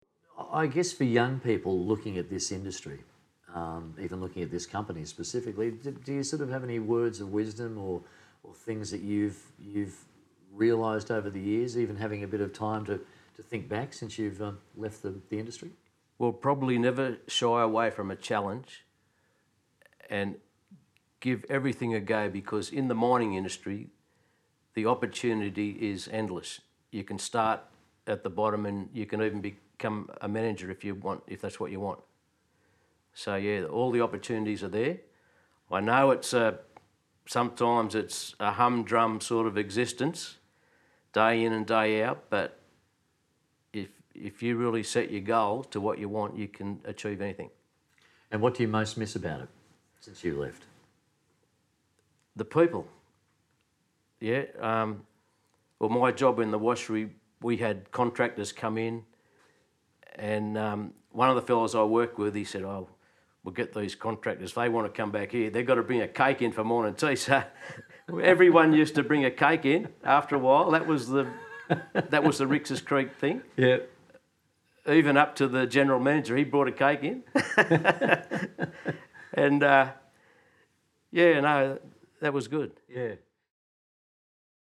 a series of interviews